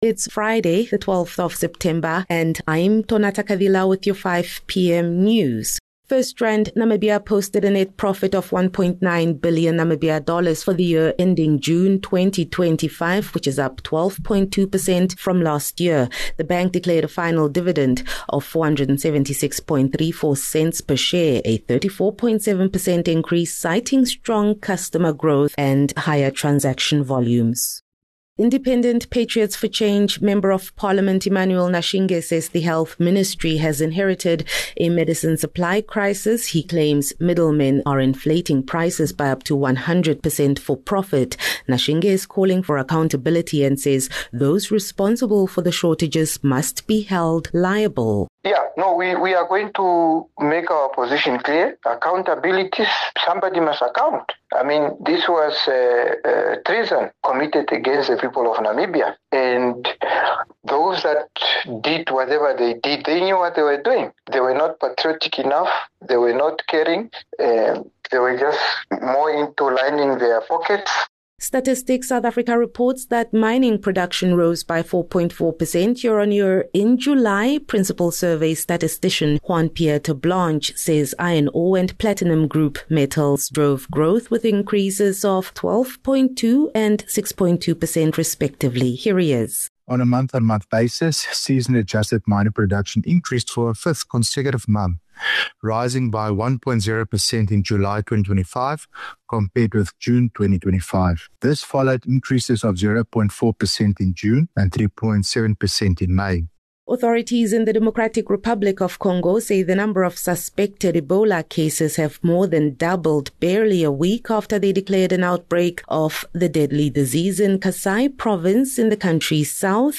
12 Sep 12 September - 5 pm news